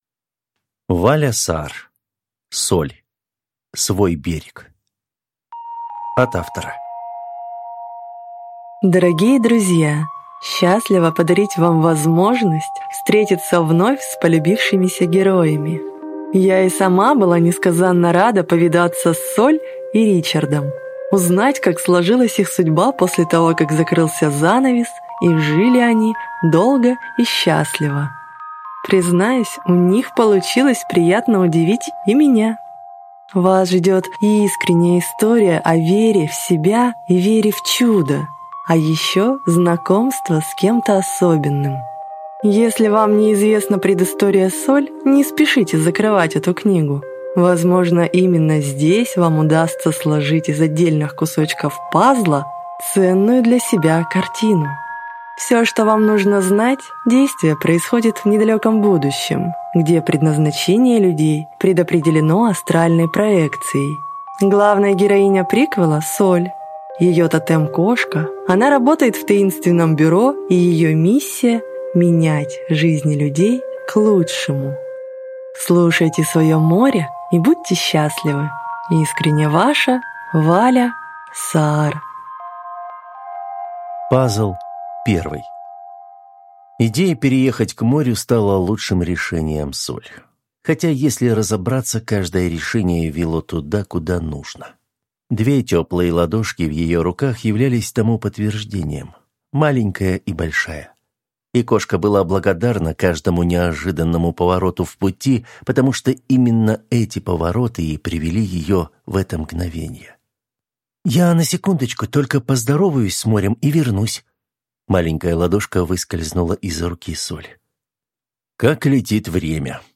Аудиокнига Соль. Свой берег | Библиотека аудиокниг